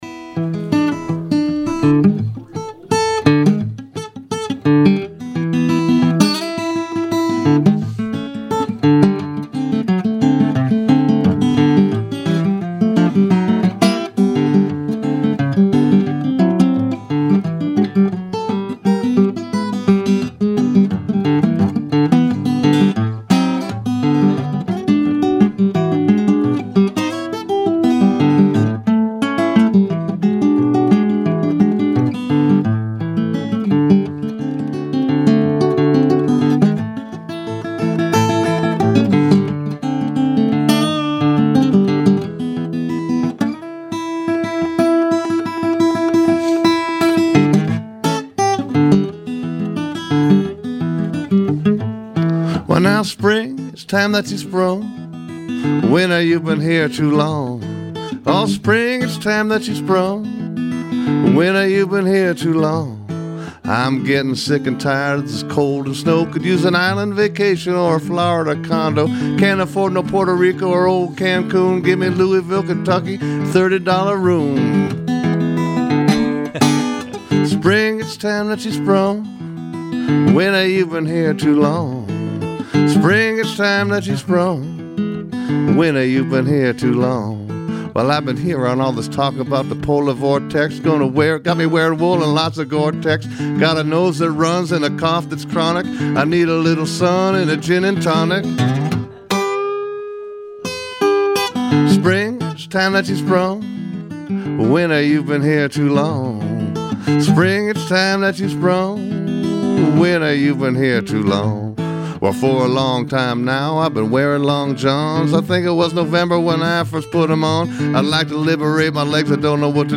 blues
to Studio A March 7 during our "Spring Forward" membership drive